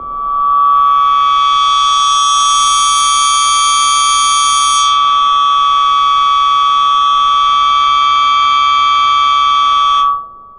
звук свиста ветра